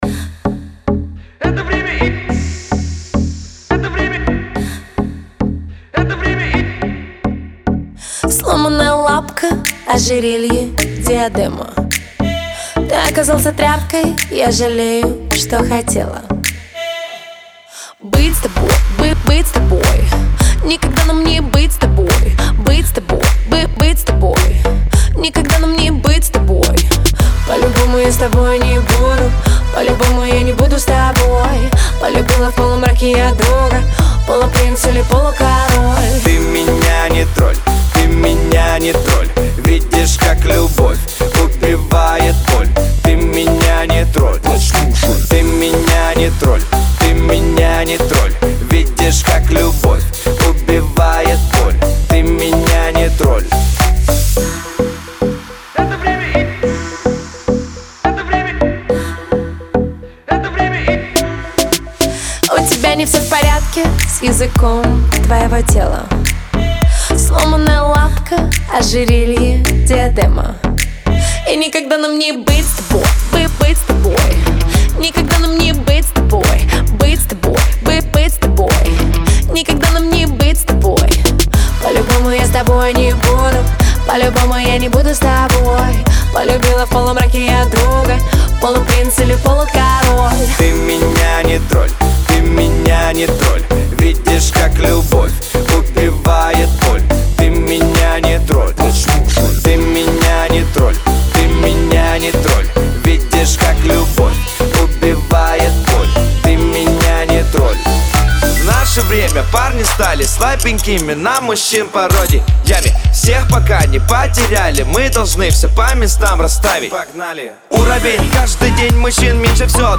Жанр: Популярная музыка